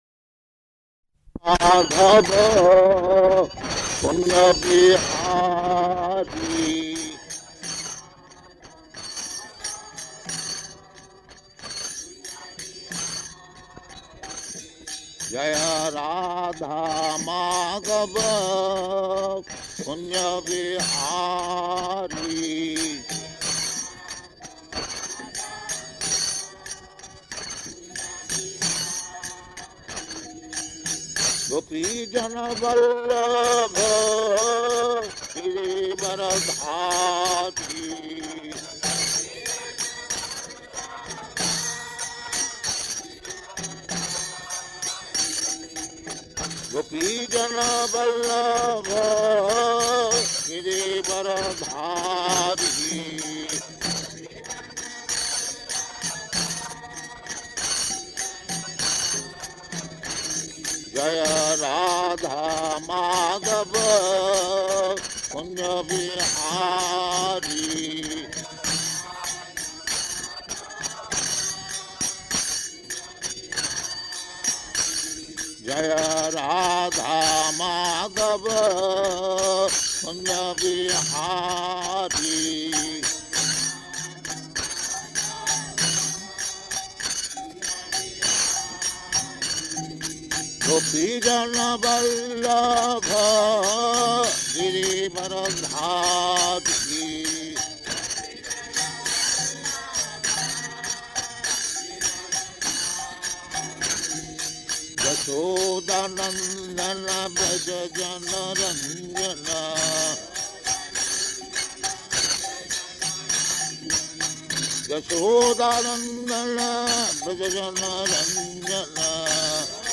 Srila Prabhupada Lectures | Why it is enforced upon me | Srimad Bhagavatam 1-2-11 | Vrindavan